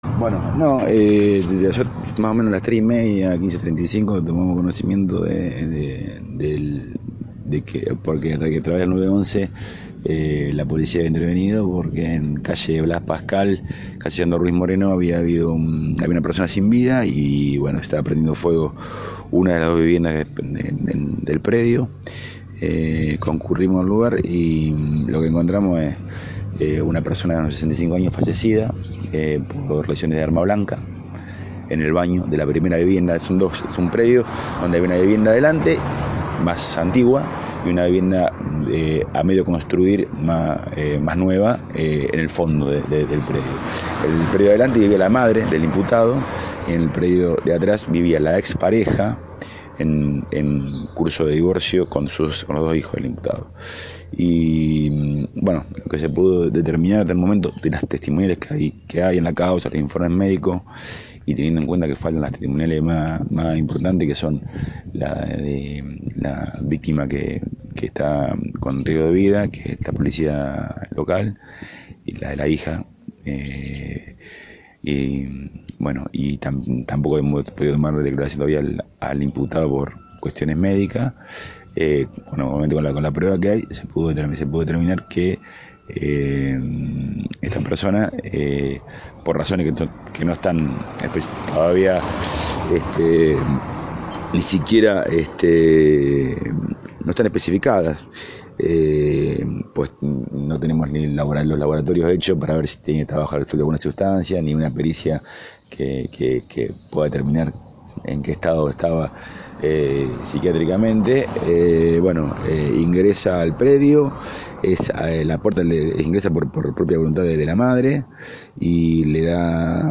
El Dr. Patricio Mujica Díaz a cargo de la investigación que echará luz sobre lo sucedido en barrio Prado Español, dialogó con Prensa Libre SN.